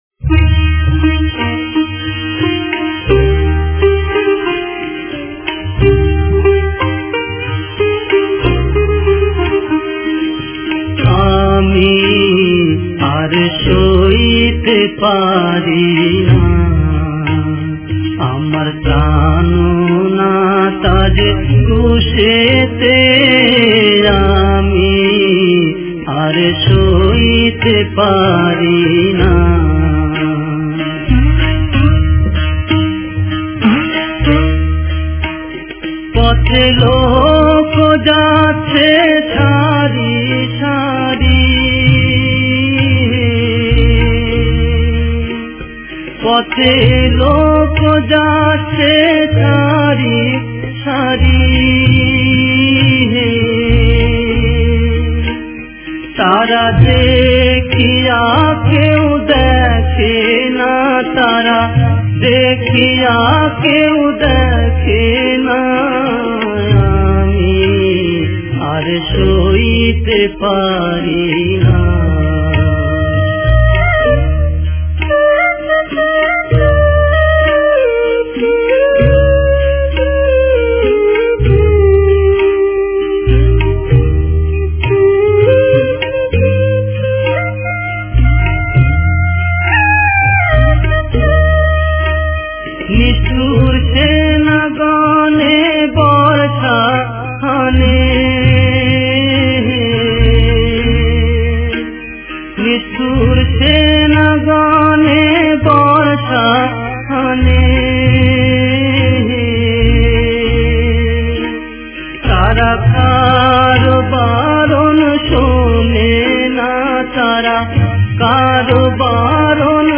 Devotional Hymns